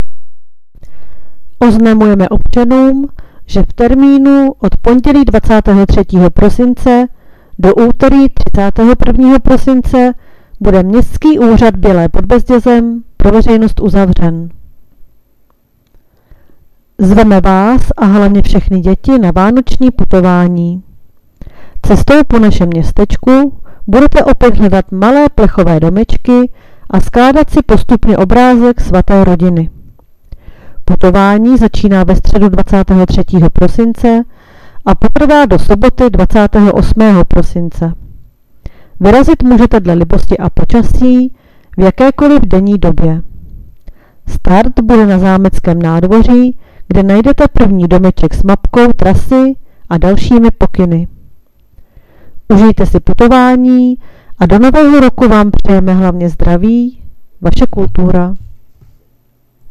Hlášení městského rozhlasu 23.12.2024